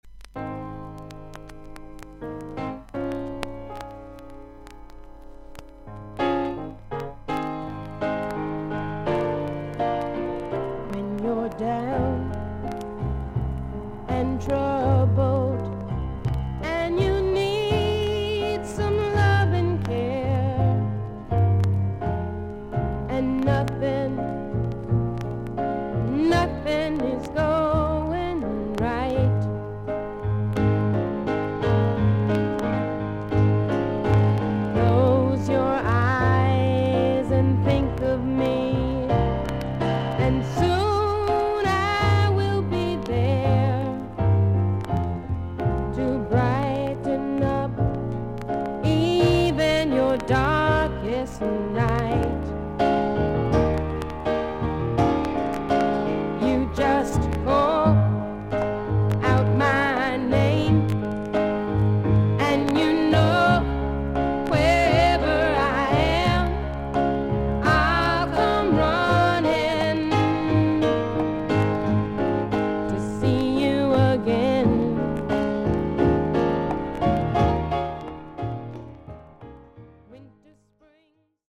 所々に少々軽いパチノイズの箇所あり。少々サーフィス・ノイズあり。音はクリアです。試聴は周回ノイズがあるB1の前半です。
女性シンガー/ソングライター。